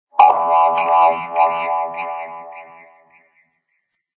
Descarga de Sonidos mp3 Gratis: videojuegos 6.